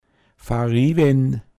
pinzgauer mundart
va(r)iwen;